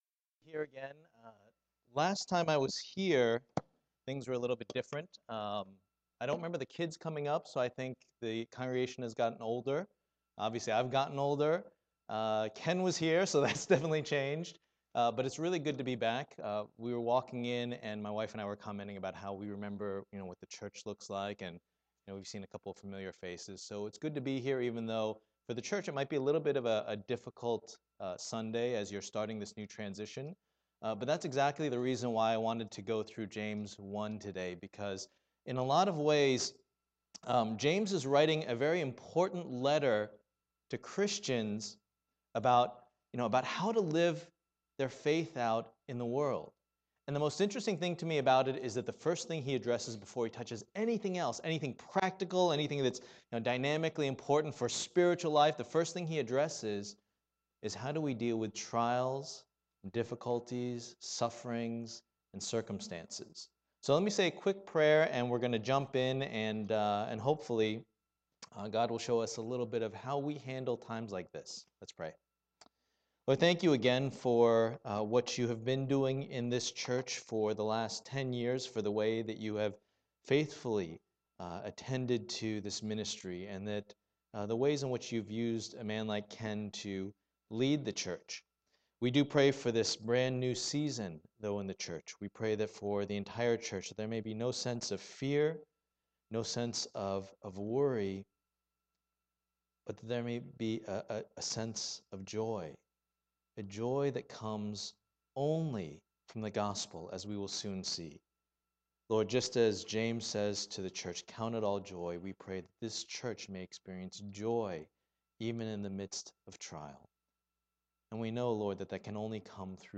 James 1:1-4 Service Type: Lord's Day %todo_render% « Make Much of Jesus The Apex of our Faith